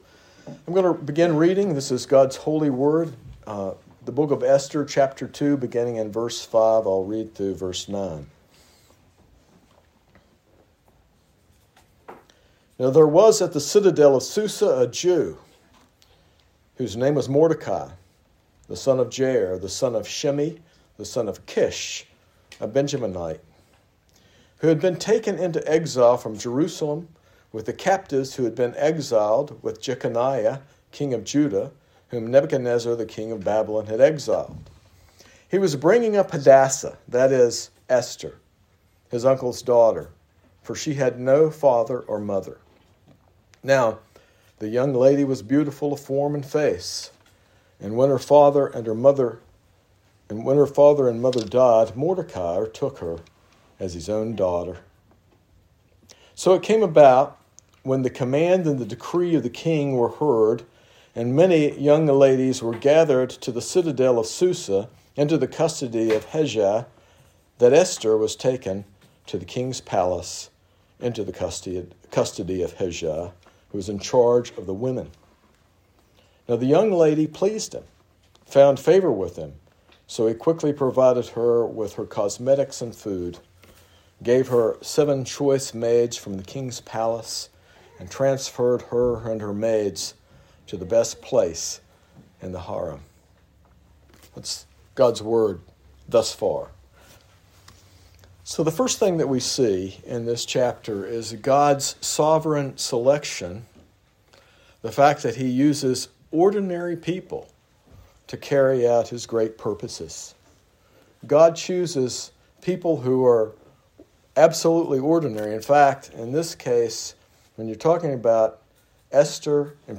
This sermon reveals how God orchestrates events behind the scenes to accomplish His purposes even when His name is not explicitly mentioned.